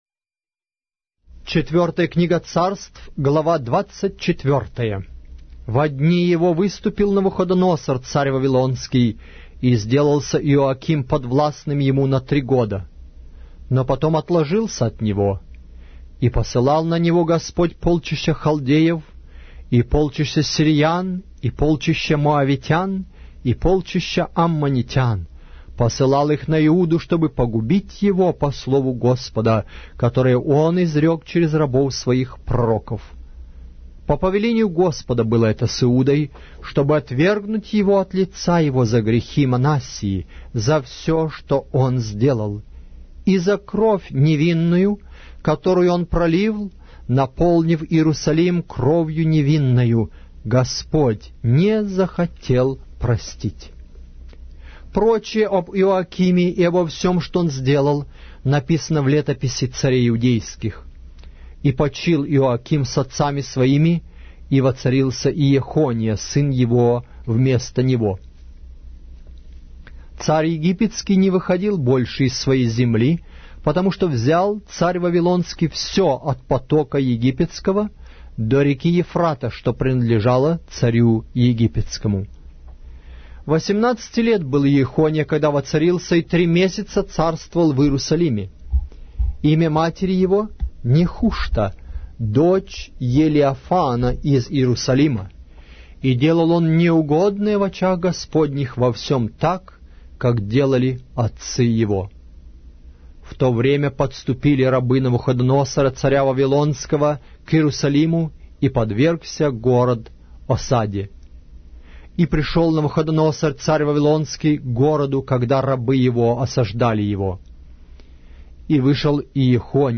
Аудиокнига: 4-я Книга Царств